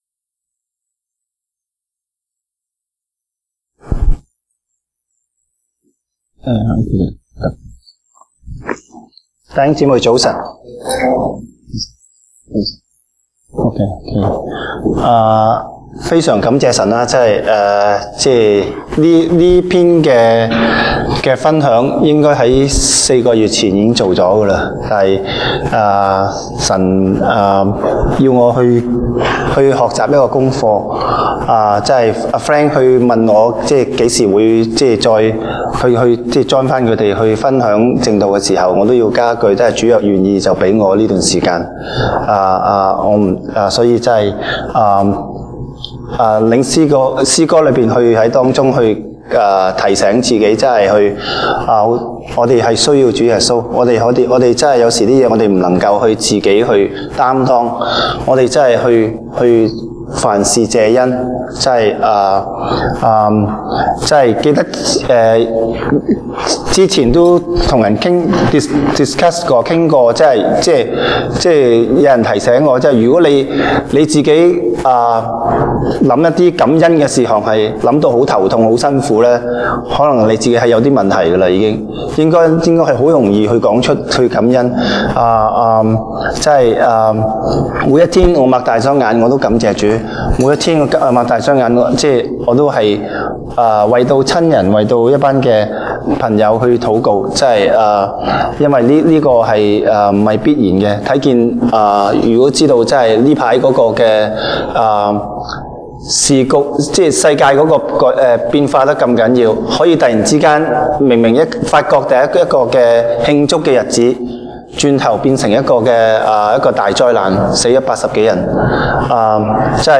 東北堂證道 (粵語) North Side: 潔淨自己，敬畏神
哥林多後書 2 Corinthians 7:1-13 Service Type: 東北堂證道 (粵語) North Side (First Church) Topics: 潔淨自己，敬畏神